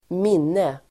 Uttal: [²m'in:e]